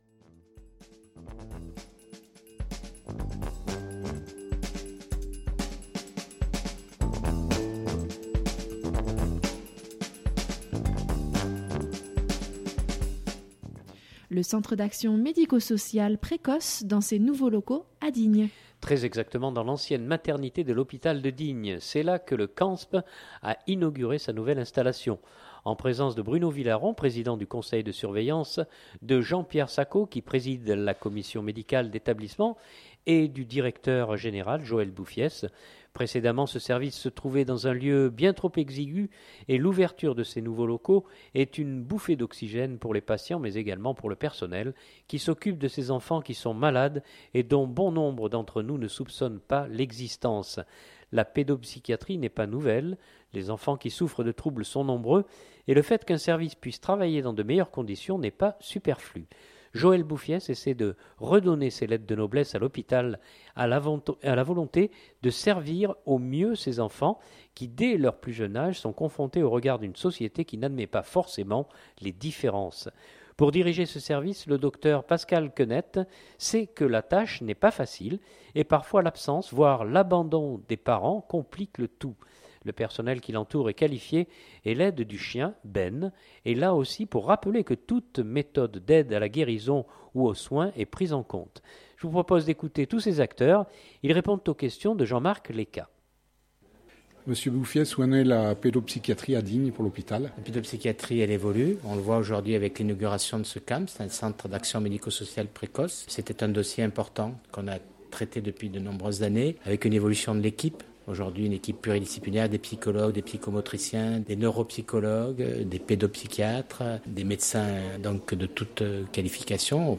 Je vous propose d’écouter tous ces acteurs, ils répondent aux questions